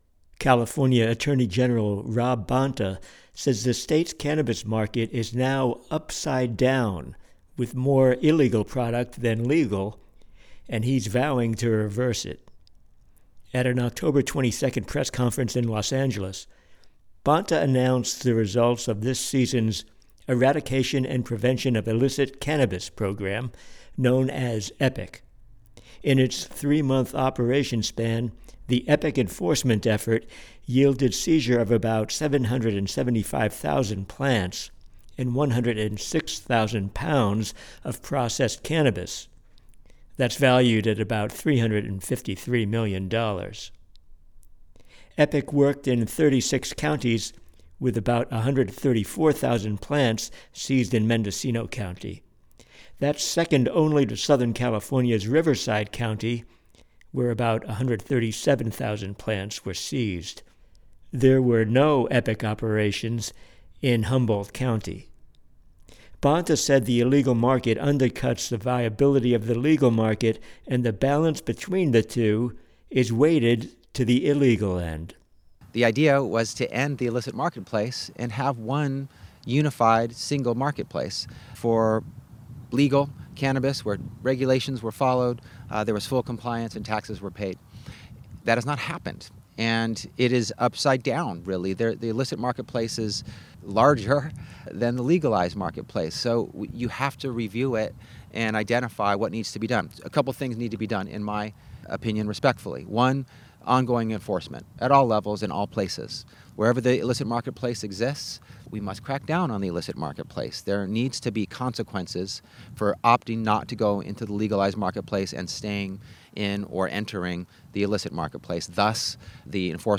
A summary of the state's cannabis enforcement program and the scale of the illegal market was detailed by Attorney General Rob Bonta in a press conference.